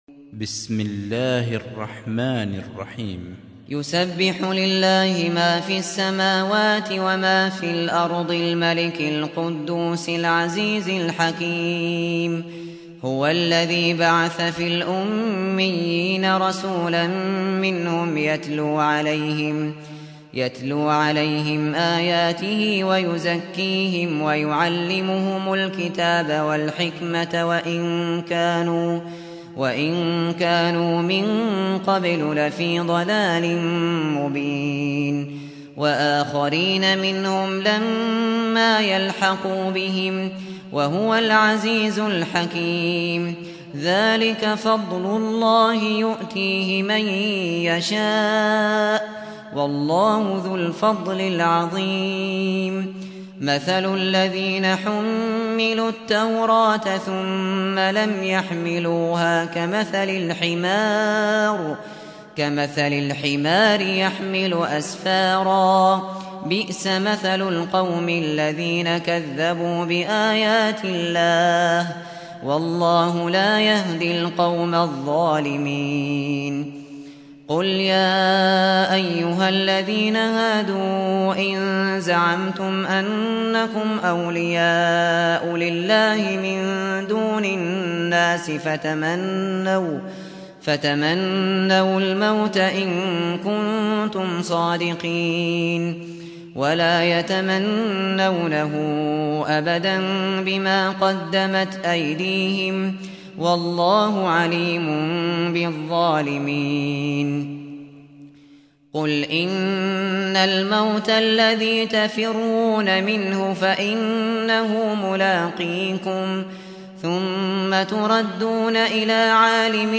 سُورَةُ الجُمُعَةِ بصوت الشيخ ابو بكر الشاطري